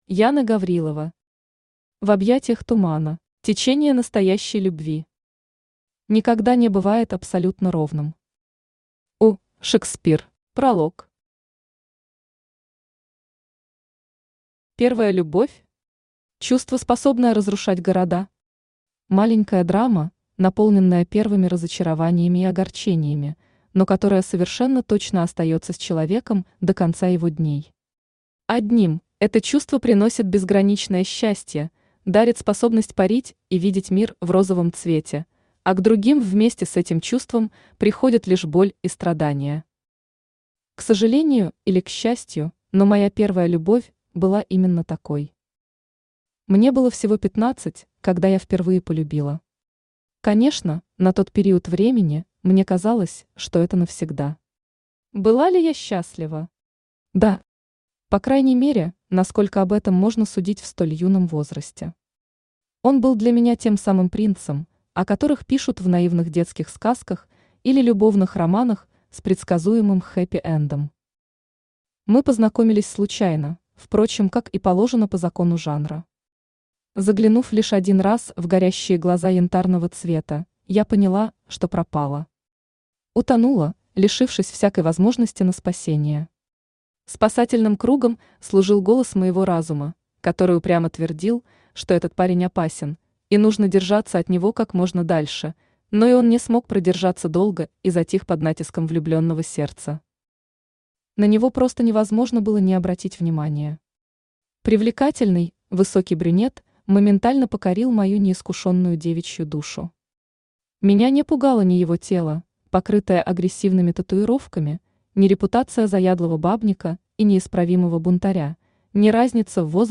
Аудиокнига В объятиях Тумана | Библиотека аудиокниг
Aудиокнига В объятиях Тумана Автор Яна Гаврилова Читает аудиокнигу Авточтец ЛитРес.